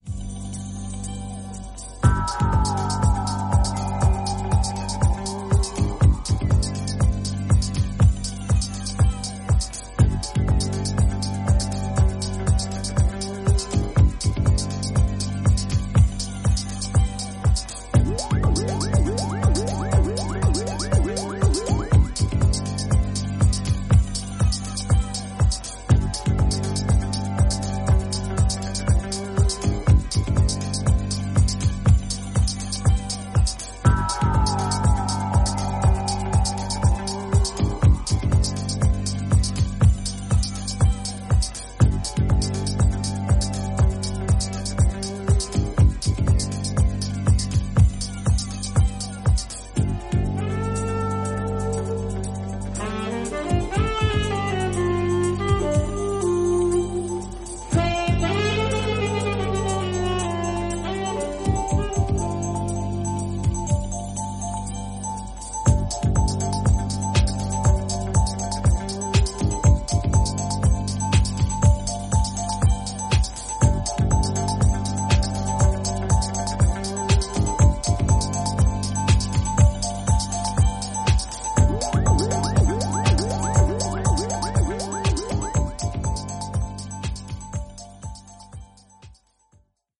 ハウス/ダウンビートを軸にバレアリックな雰囲気を纏った、ノスタルジックで素敵な1枚。